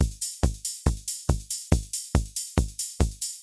描述：由减法合成器创造的低音鼓、hihat和骑钹，以140bpm的速度跳动着经典的techno节拍
Tag: 140BPM 基础知识 bassdrum 踩镲 ridecymbal TECHNO 精神恍惚